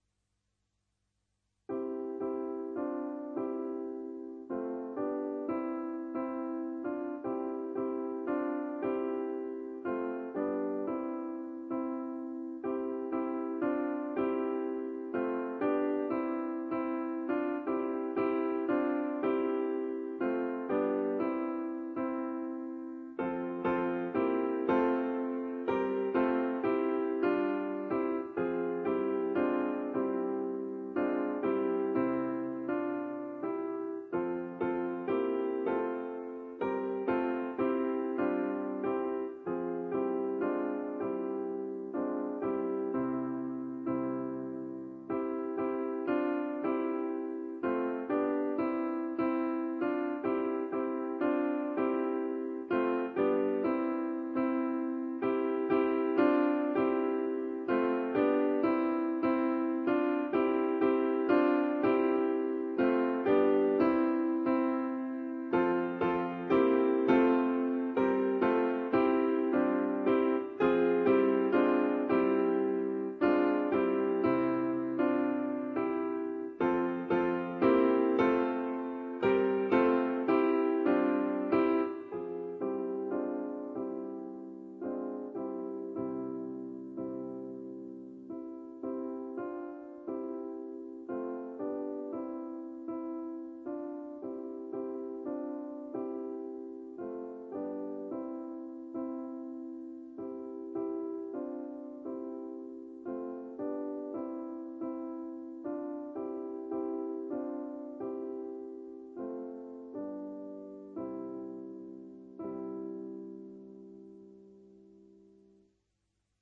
ADATTAMENTI PER PIANO